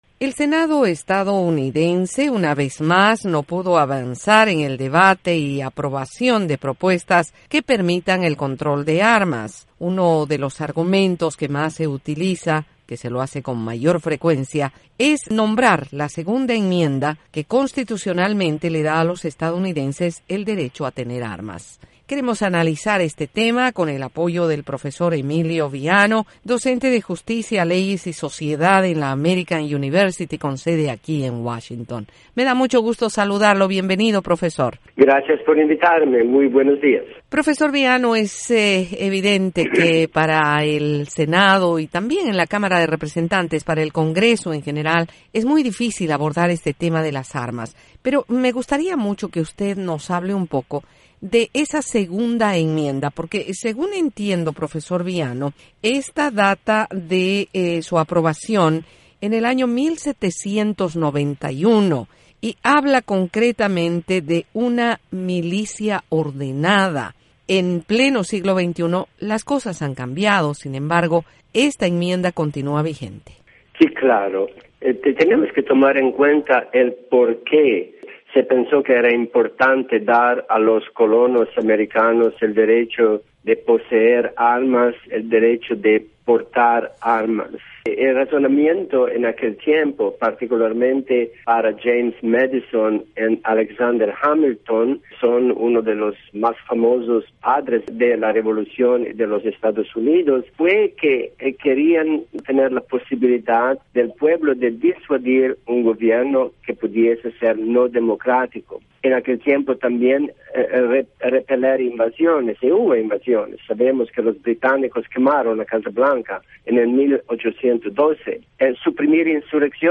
afirmó en entrevista con la Voz de América que la Segunda y la Novena Enmienda en la Constitución de Estados Unidos son los elementos básicos a la hora de esta discusión.